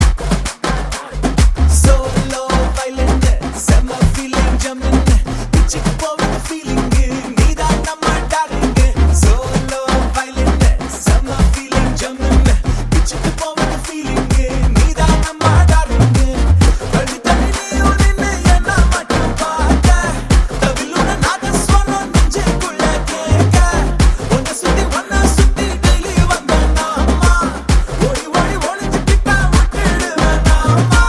Category Tamil